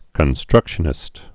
(kən-strŭkshə-nĭst)